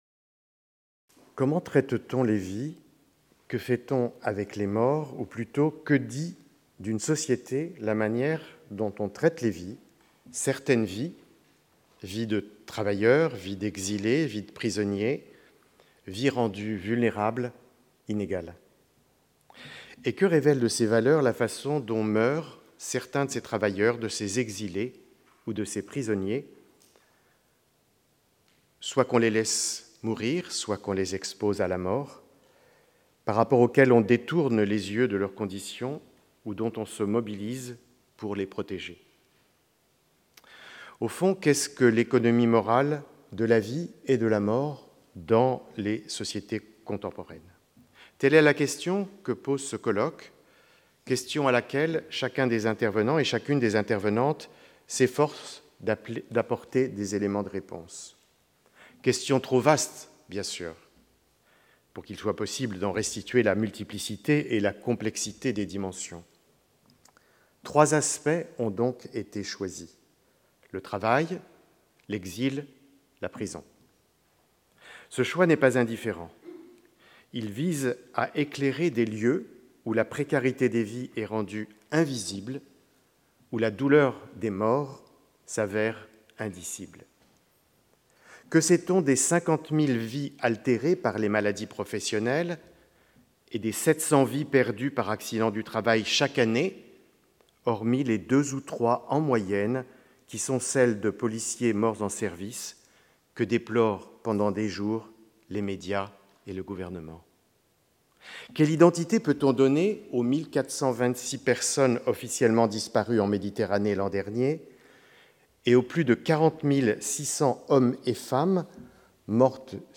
Symposium 17 Jun 2021 09:30 to 09:45 Didier Fassin Invisible lives, unspeakable deaths : introduction